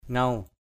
/ŋau/ maong: ‘hanguw’ hz~|